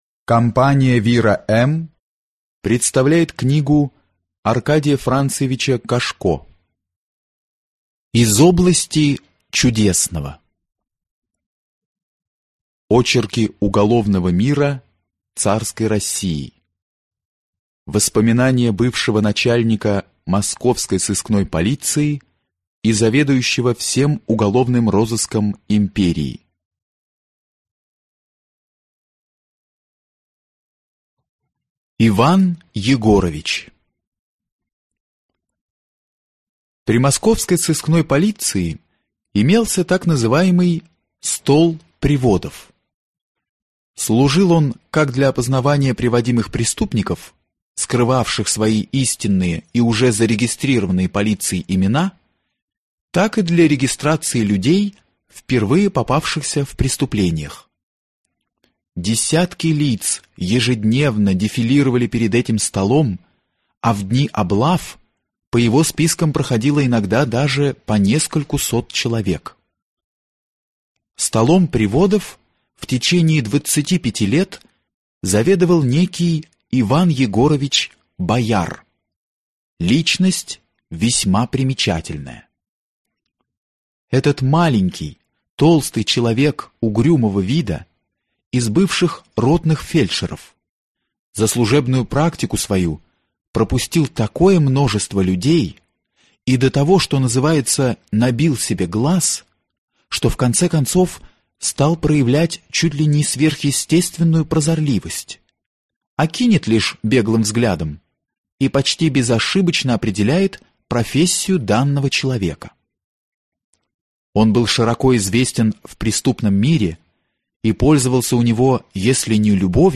Аудиокнига Из области чудесного | Библиотека аудиокниг
Прослушать и бесплатно скачать фрагмент аудиокниги